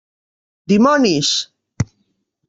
[diˈmɔ.nis]